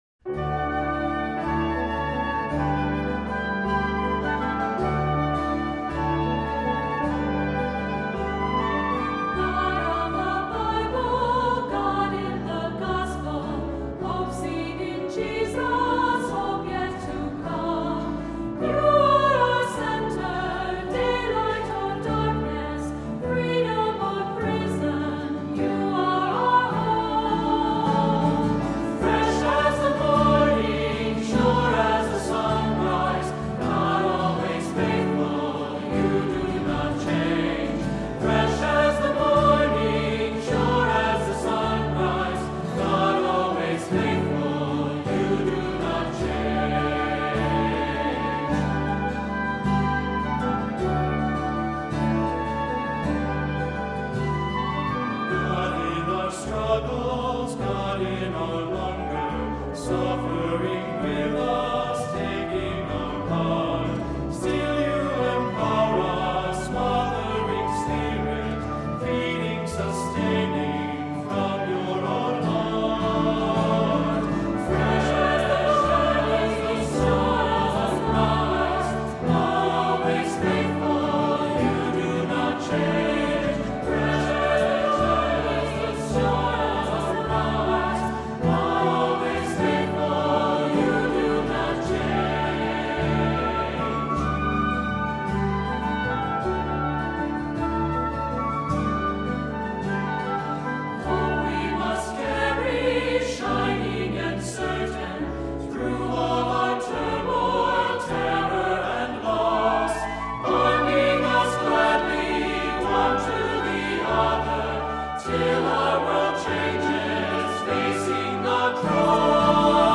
Accompaniment:      Keyboard
Music Category:      Christian